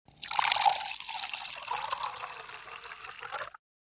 Agua llenando un vaso: Efectos de sonido
Categoría: Efectos de Sonido
Este efecto de sonido ha sido grabado para capturar la naturalidad del proceso de llenar un vaso con agua, proporcionando un sonido limpio y claro que se integrará perfectamente en tus proyectos.
Agua en vaso.mp3